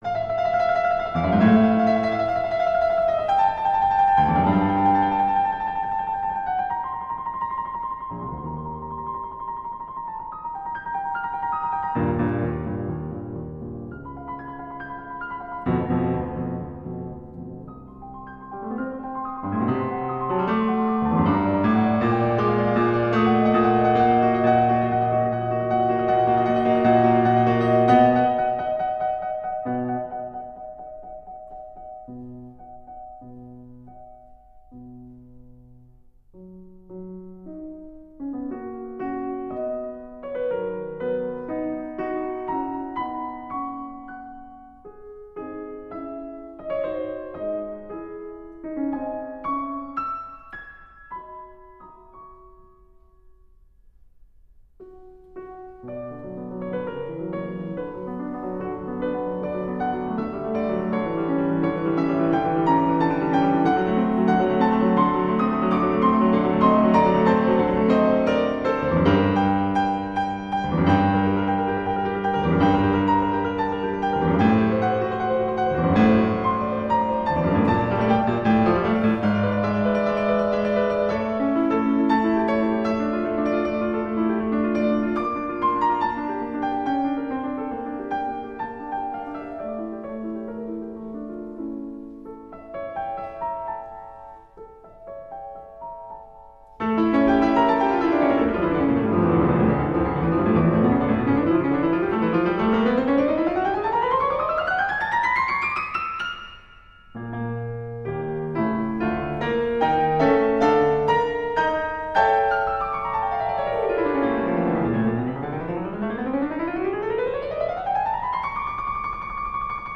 Incontro